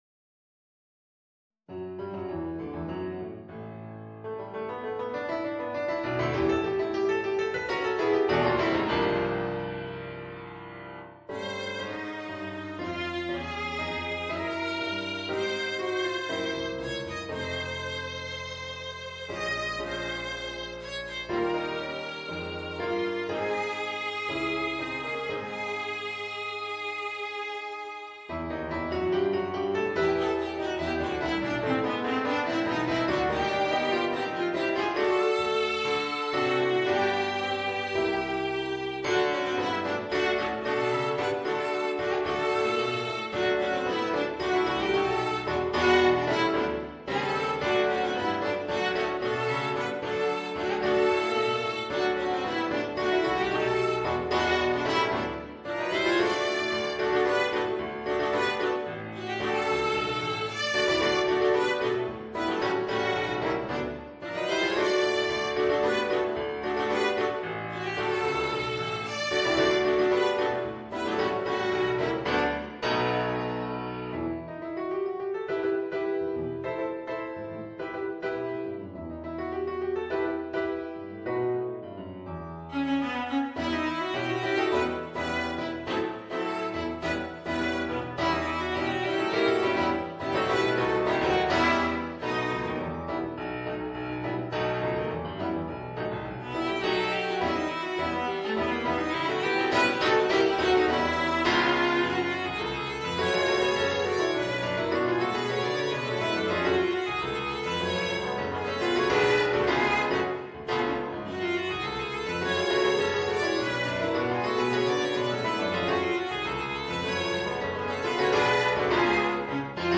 Voicing: Viola and Piano